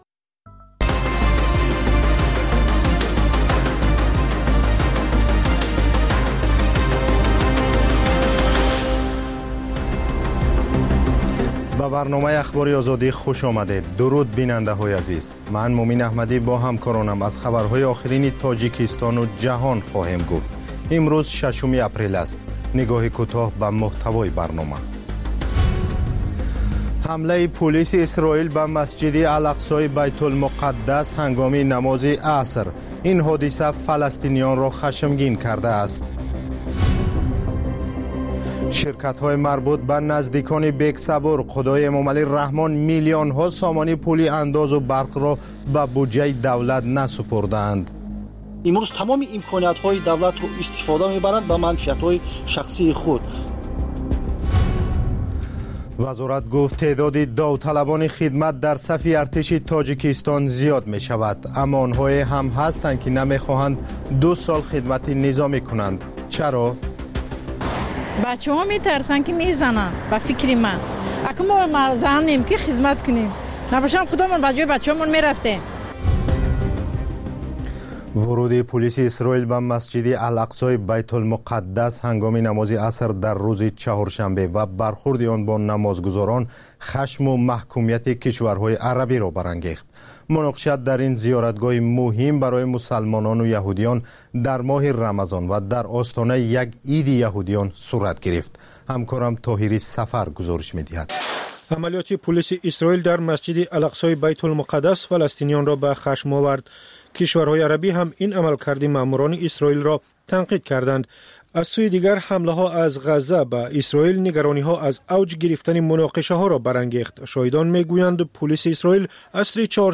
Фишурдаи охирин ахбори ҷаҳон, гузоришҳо аз Тоҷикистон, гуфтугӯ ва таҳлилҳо дар барномаи бомдодии Радиои Озодӣ.